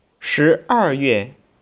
(Click on any Chinese character to hear it pronounced.
shieryue.wav